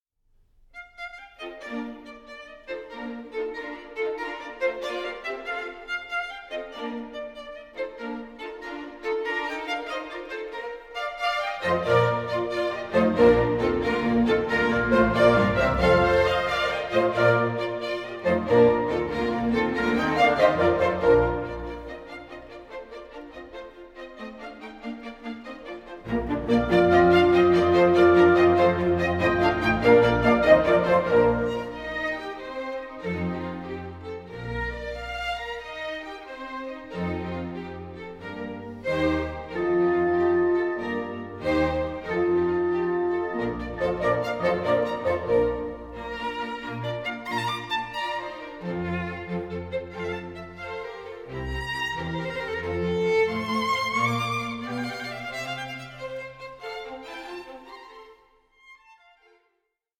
Andante 12:40